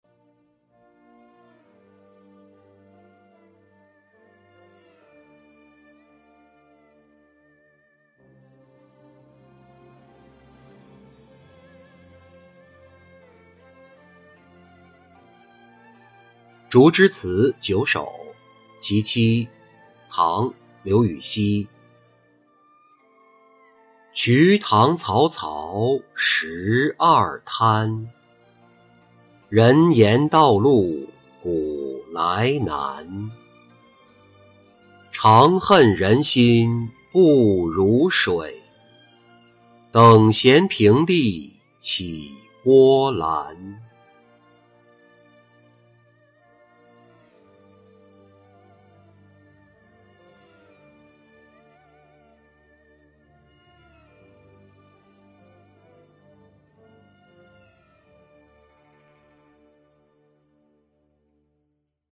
竹枝词九首·其七-音频朗读